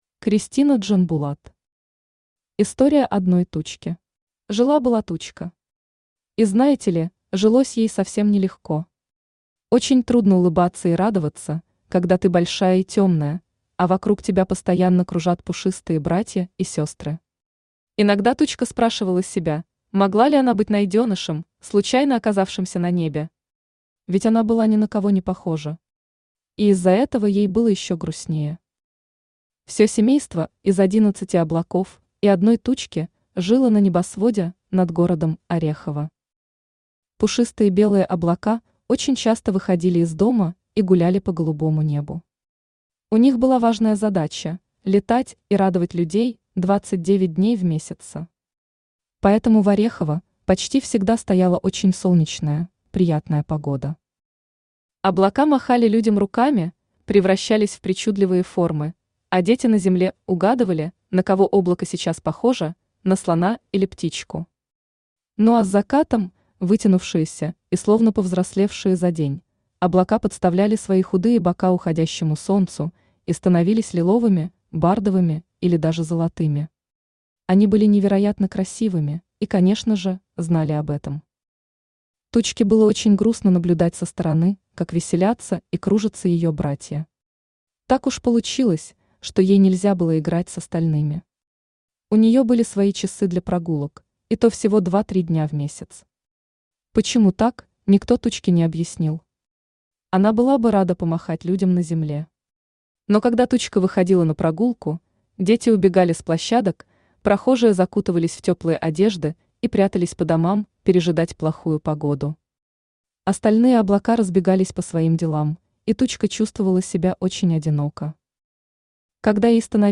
Аудиокнига История одной Тучки | Библиотека аудиокниг